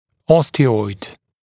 [ˈɔstɪɔɪd][ˈостиойд]остеоид, остеоидная ткань